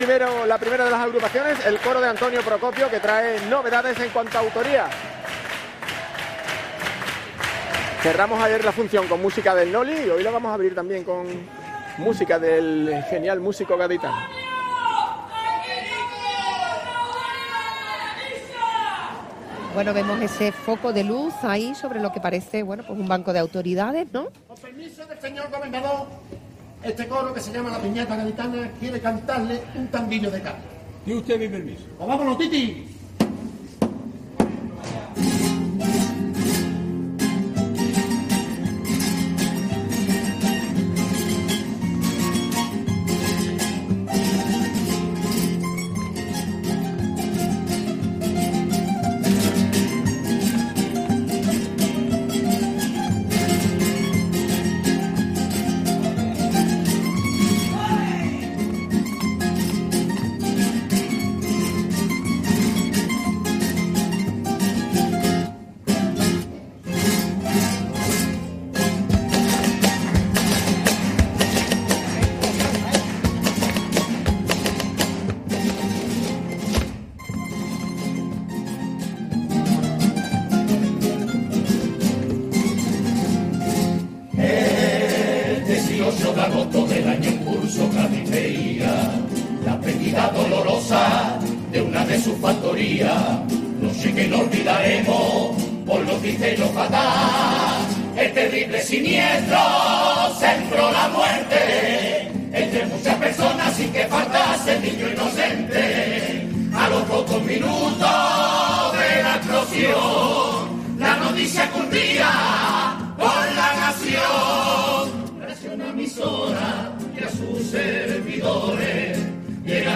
Concurso Oficial de Agrupaciones del Carnaval de Cádiz